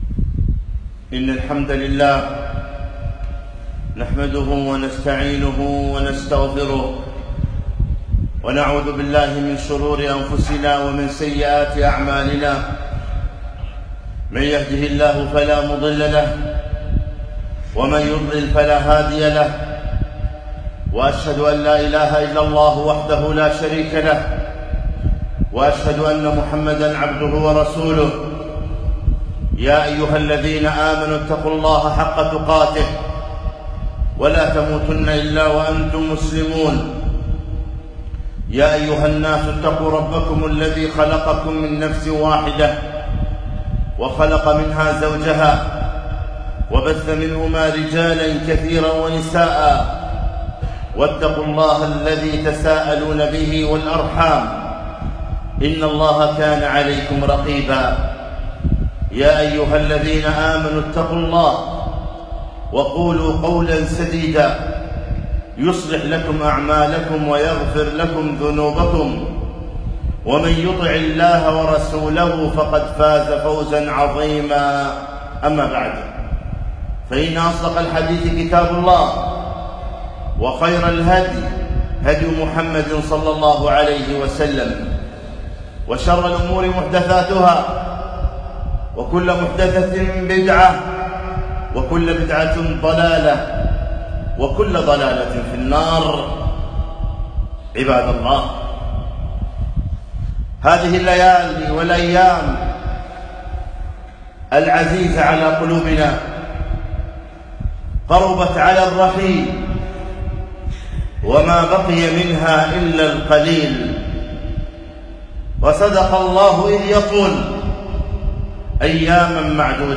خطبة - وصايا في ختام رمضان